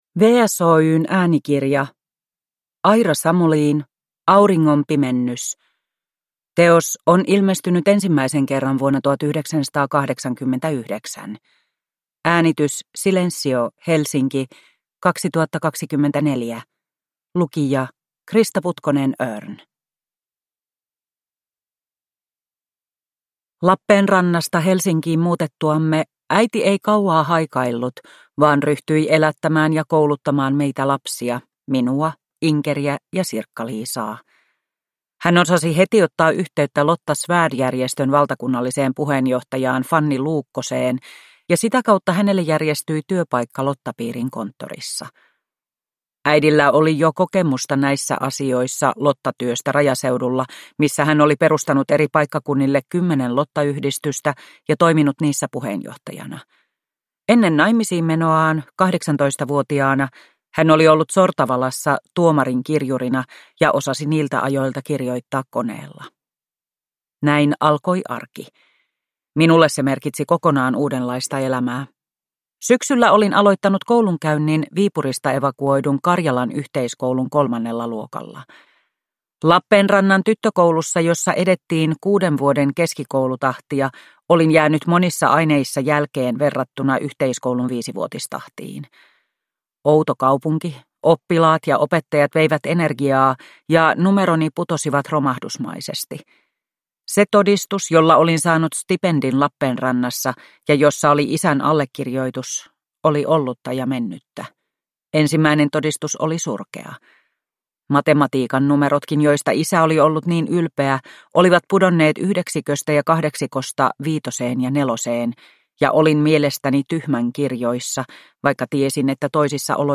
Auringonpimennys – Ljudbok